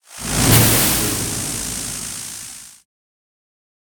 spell-impact-lightning-4.ogg